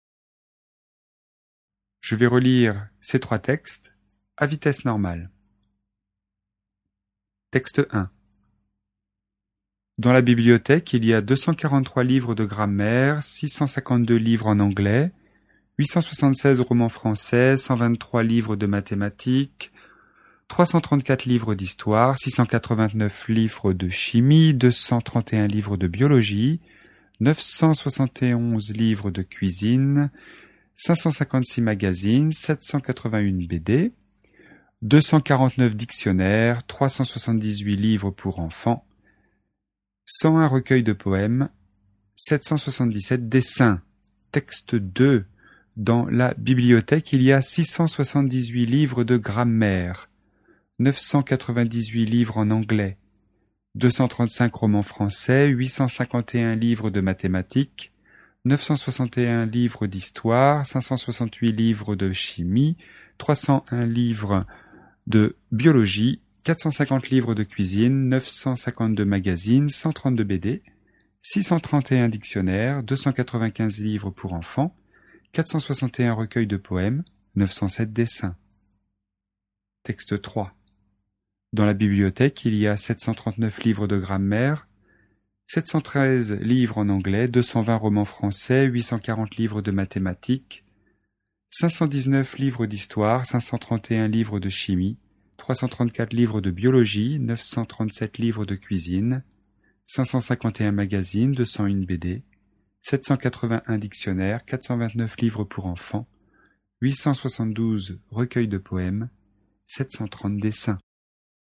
ECOUTER LES TROIS TEXTES (vitesse normale)
vitesse-normale.mp3